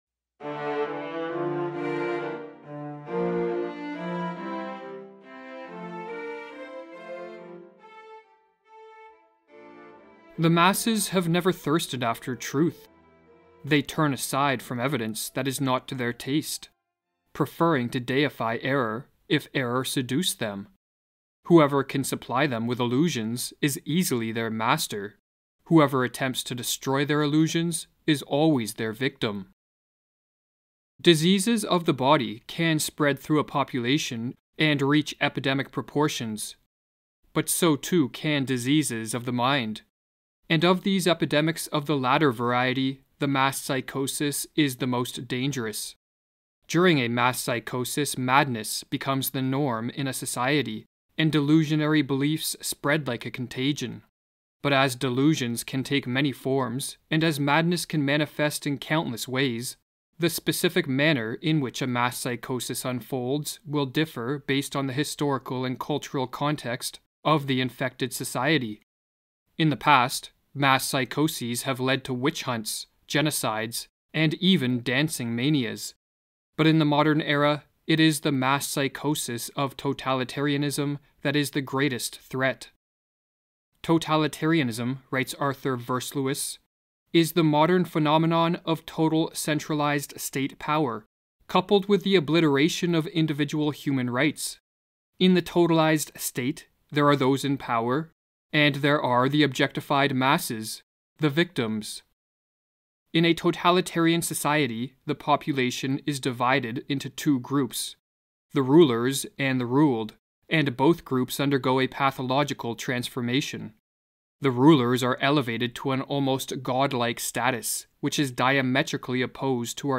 A short talk on The Manufacturing of a Mass Psychosis (highly recommended)
The presentation is formal and slightly academic, which helps bring focus on how profoundly serious and concerning present events are, as regards mass psychology.